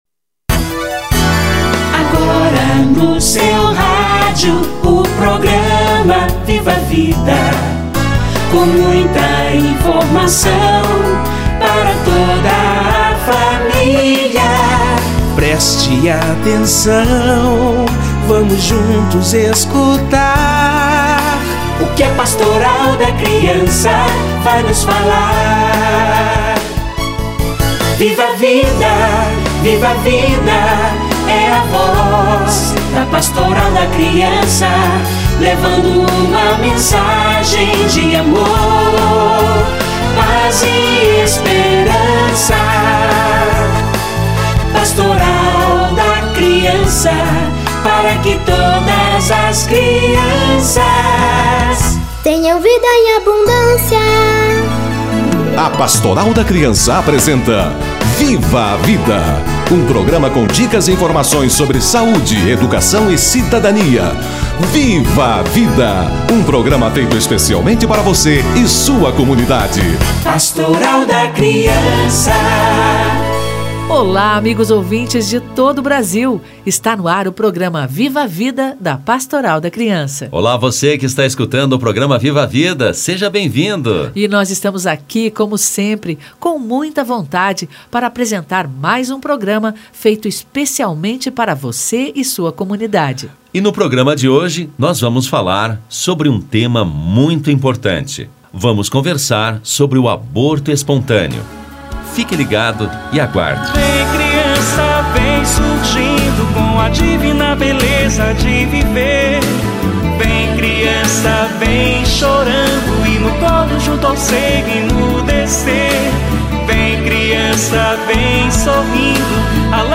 Aborto espontâneo: sofrimento em família - Entrevista